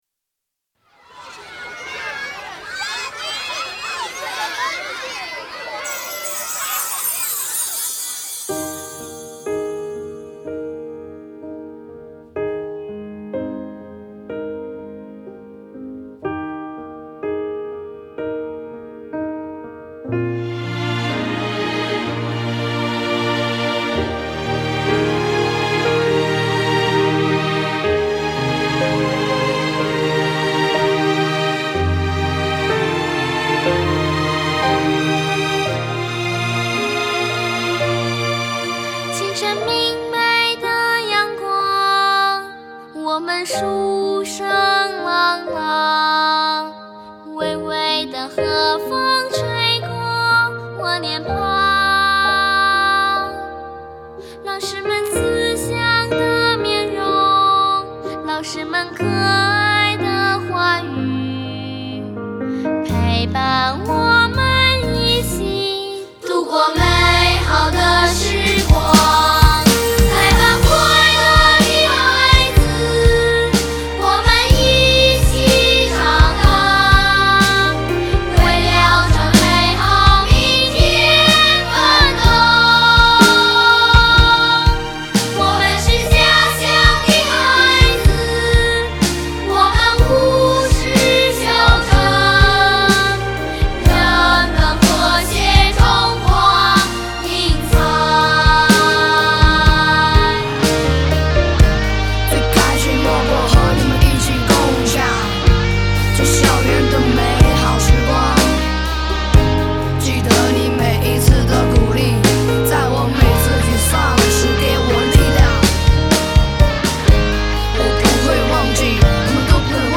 师生演绎十首
清亮的童声配以极富现代感的儿童Rap，优美而不失欢快，使歌曲更具表现力。